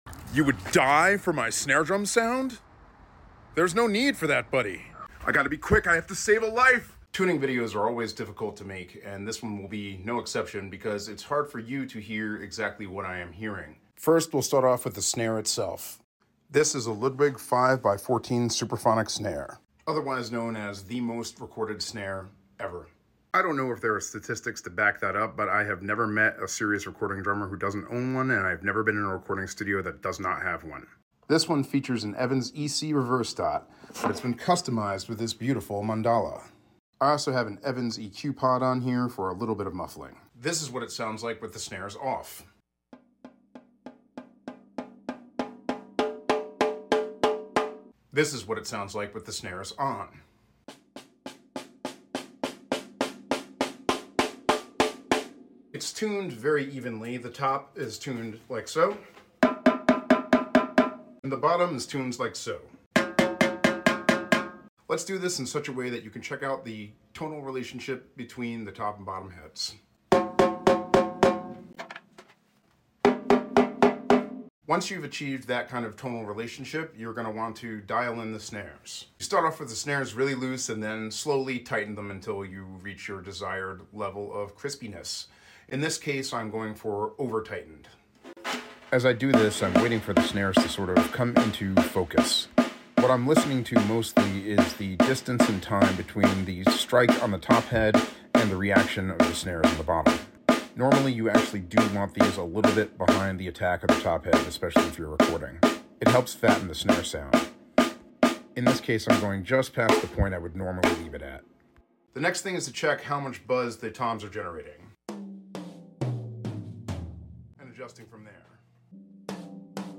Here how I am getting that snare drum sound: a cranked Ludwig Supraphonic + reverb.